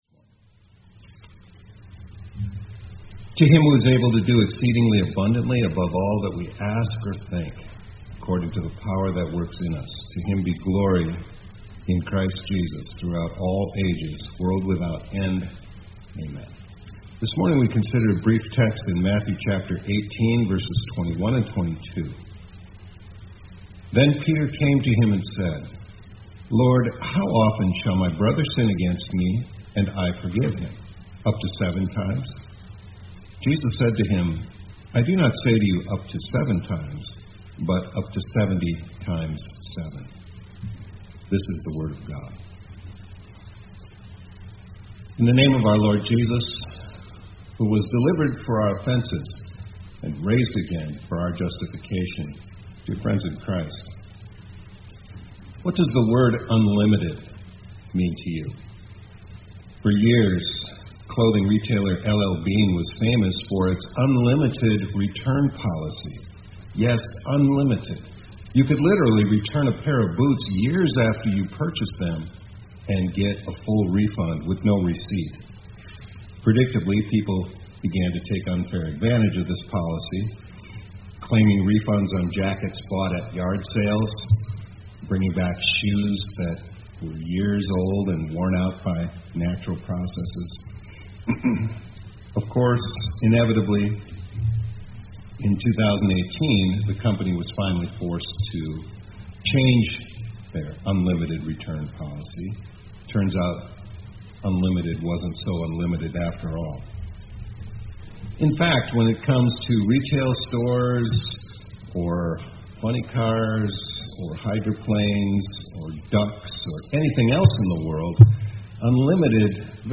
2025-04-23 ILC Chapel — God’s Forgiveness is UNLIMITED